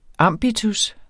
Udtale [ ˈɑmˀbitus ]